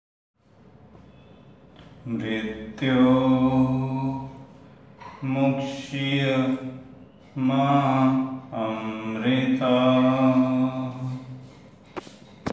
Chanting (slow for comprehension) –